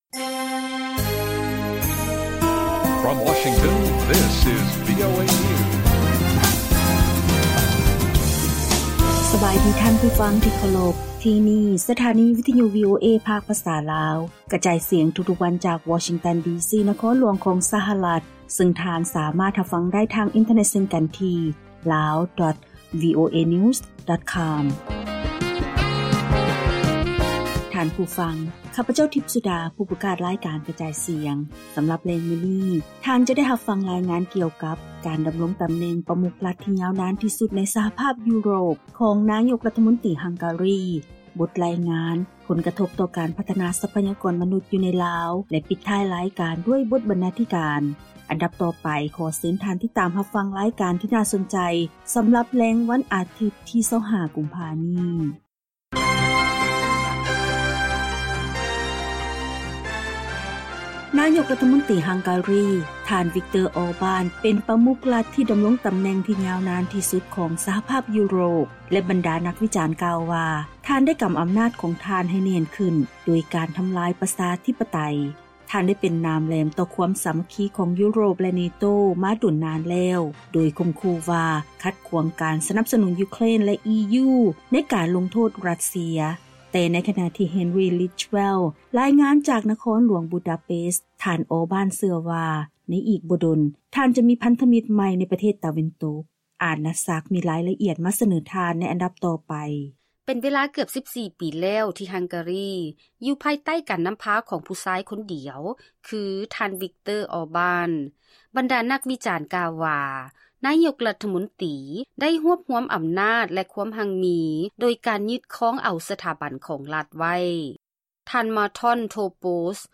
ວີໂອເອພາກພາສາລາວ ກະຈາຍສຽງທຸກໆວັນ, ບົດລາຍງານສໍາລັບແລງມື້ນີ້ ມີດັ່ງນີ້: 1. ນາຍົກລັດຖະມົນຕີຮັງກາຣີ ຜູ້ທີ່ຖືກໂດດດ່ຽວໃນຢູໂຣບ ຫວັງໃຫ້ທ່ານທຣຳ ກັບຄືນມາ, 2. ລາວເປັນ 1 ໃນ 15 ປະເທດທີ່ມີອັດຕາງົບປະມານການສຶກສາຕໍ່າທີ່ສຸດໃນໂລກ ຍ້ອນລັດຖະບານຕ້ອງຫັກເງິນສ່ວນນຶ່ງໄວ້ ໃຊ້ໜີ້ຕ່າງປະເທດ, ແລະ ລາຍການທີ່ໜ້າສົນໃຈອື່ນໆ.